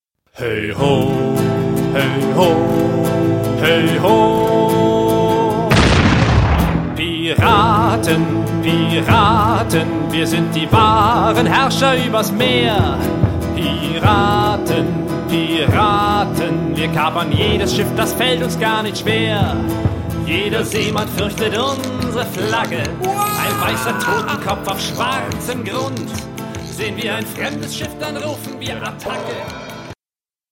Lieder zum Mitsingen, Mitmachen und Träumen